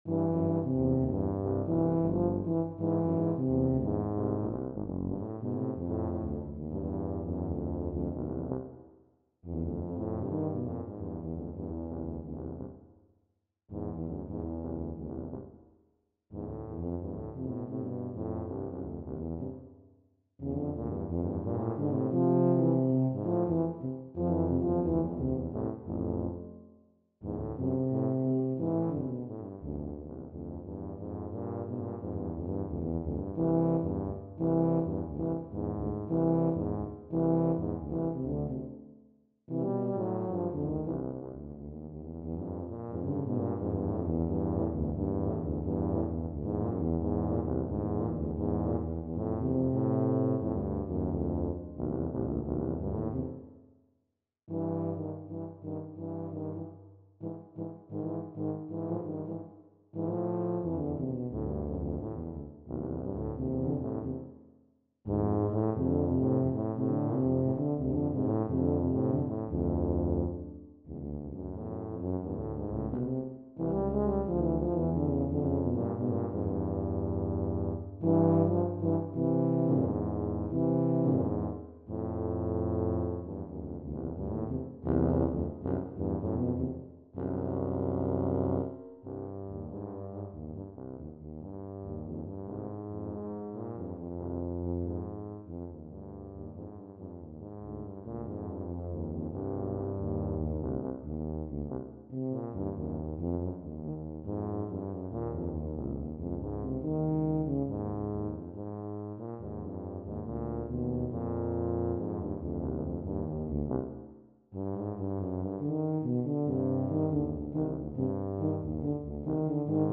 Gattung: für 2 Tuben
Besetzung: Instrumentalnoten für Tuba